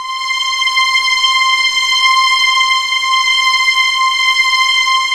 WARM VIOL LM 1.wav